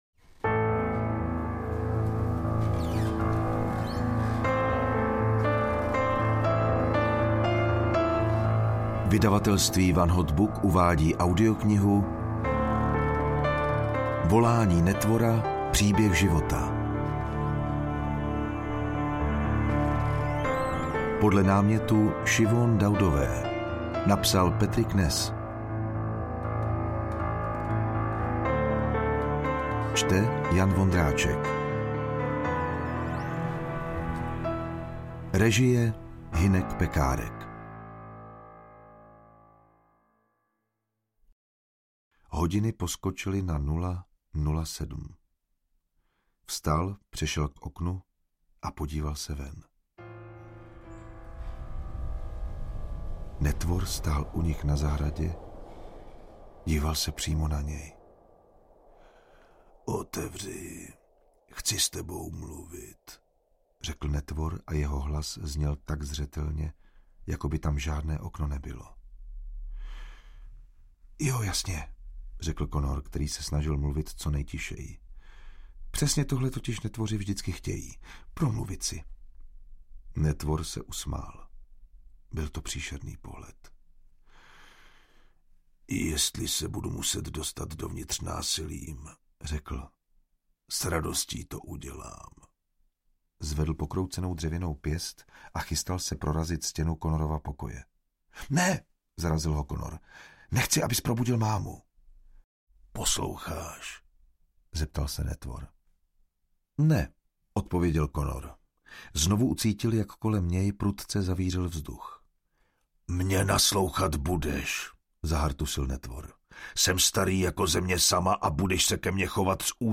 Volání netvora audiokniha
Ukázka z knihy